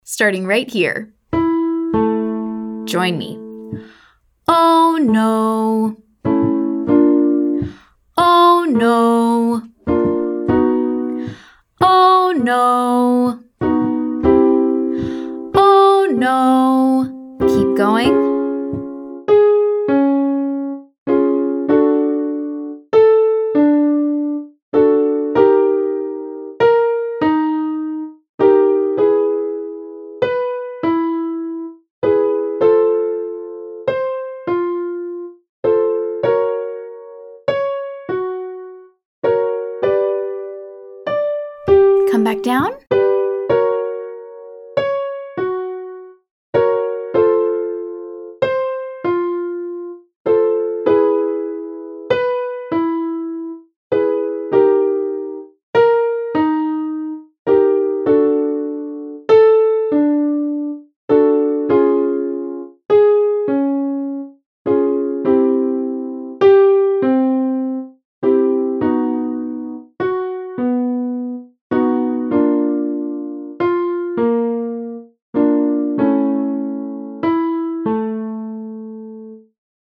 Our exercise for glottal onset is just the phrase “oh no” [demonstrate 5-1]. We’re listening for a clean start, not a scoop or an H sound.
Exercise 1: Glottal onset - OH no 5-1